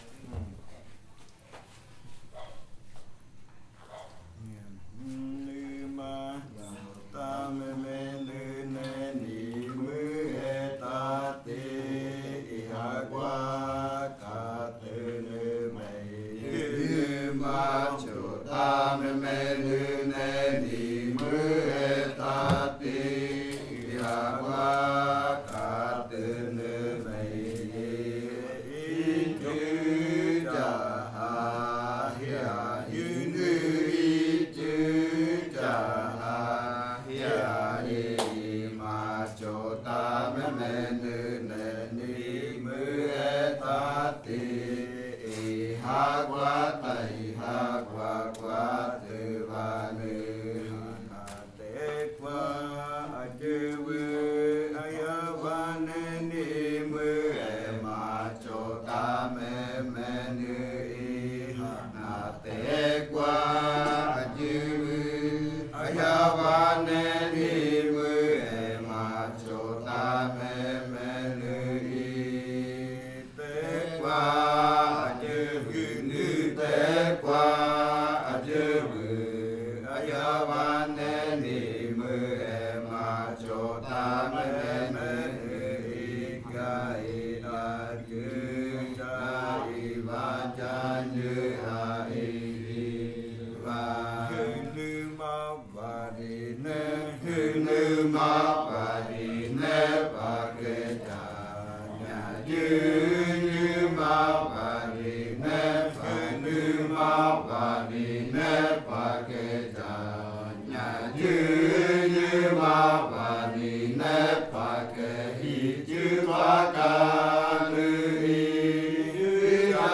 Leticia, Amazonas (Colombia)
Canto del ritual baaja (guaduas) del pueblo bora.
Song from the Baaja ritual (bamboo) of the Bora people.